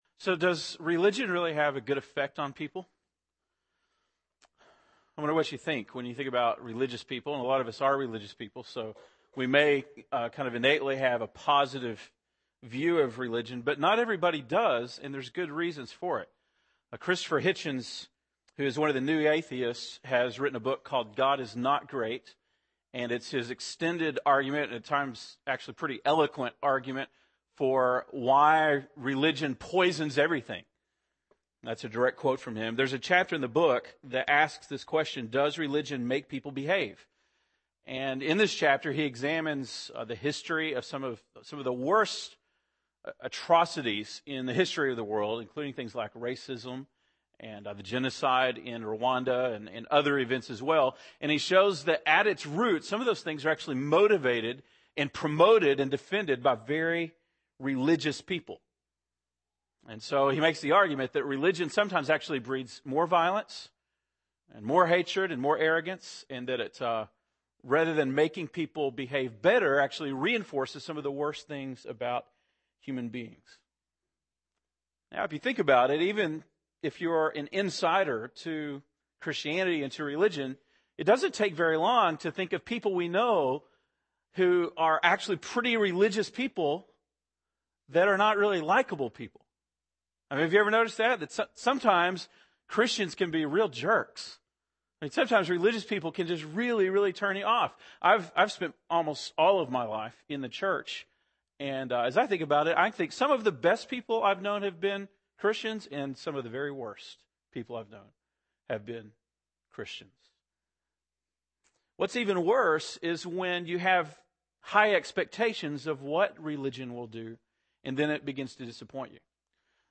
October 4, 2009 (Sunday Morning)